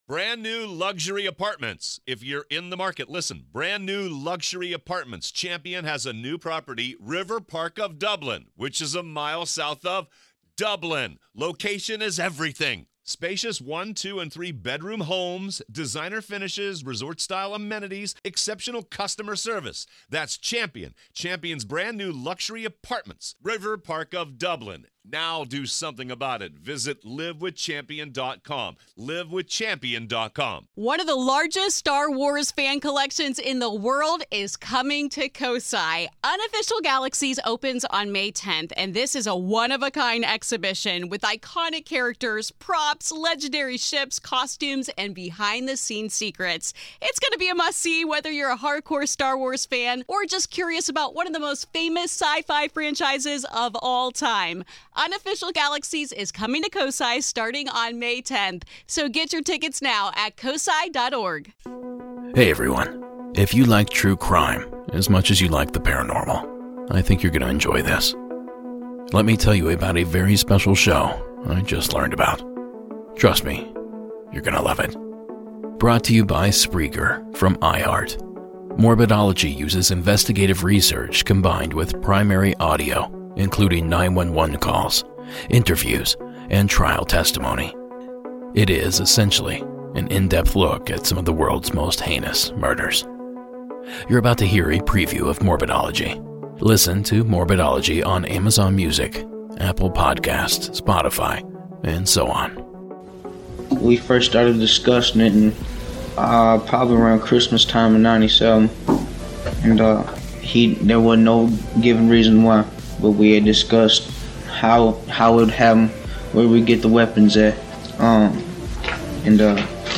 Brought to you by Spreakerfrom iHeart, Morbidology uses investigative research combined withprimary audio including 911 calls, interviews and trial testimony. It isessentially an in-depth look at some of the world's most heinousmurders. You’re about to hear a preview of Morbidology.